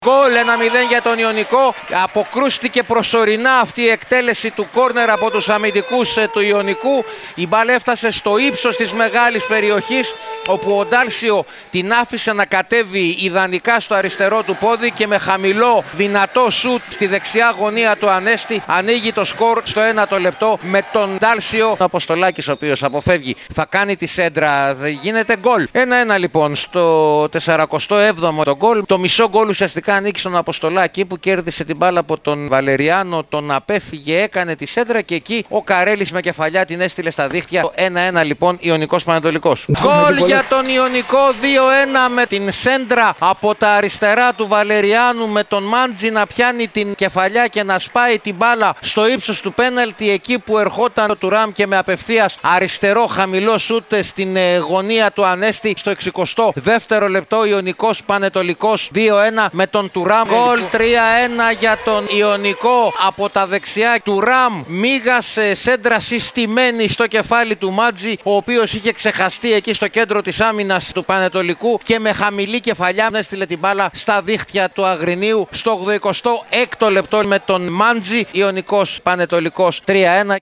Ακούστε τα γκολ που σημειώθηκαν στα παιχνίδια της τελευταίας αγωνιστικής των έτσι όπως μεταδόθηκαν από την συχνότητα της ΕΡΑΣΠΟΡ.